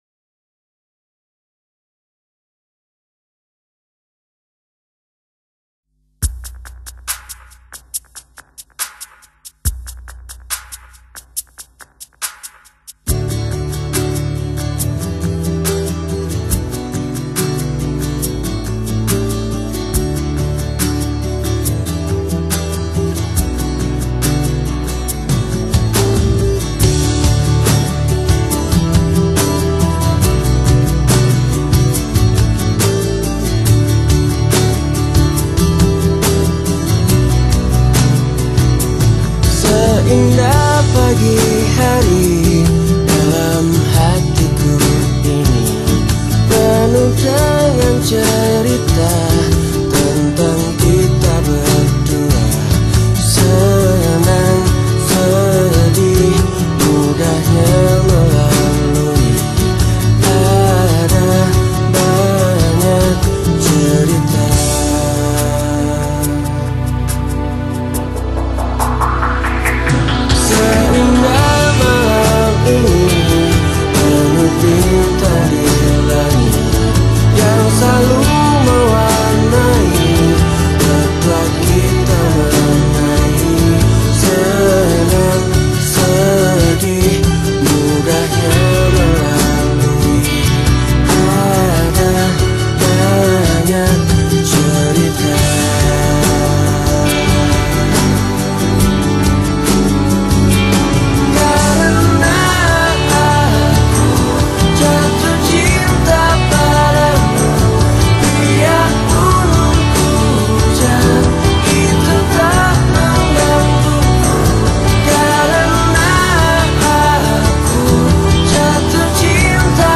Band ini umumnya bergenre pop.